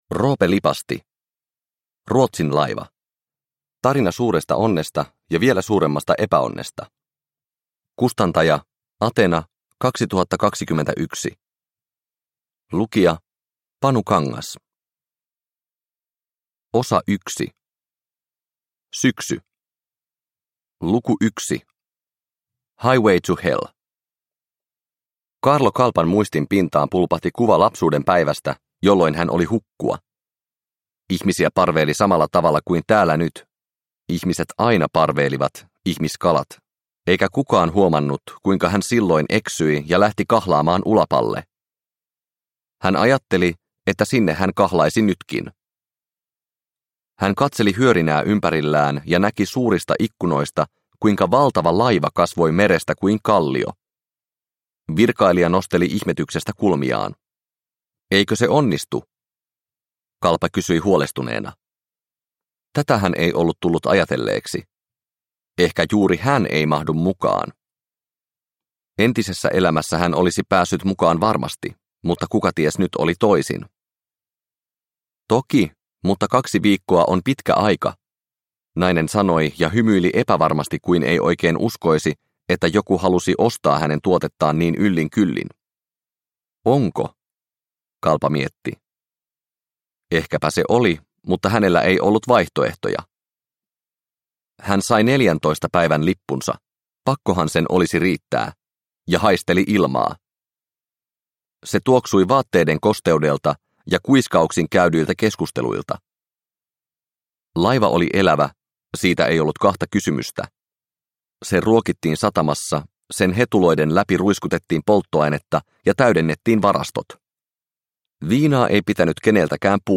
Ruotsinlaiva – Ljudbok – Laddas ner